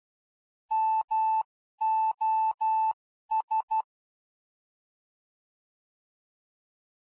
Fox hunt beacons are identified by morse code signals.
Morse code identifiers of the foxes
3 MOS — — — — — ∙ ∙ ∙ [ogg][mp3]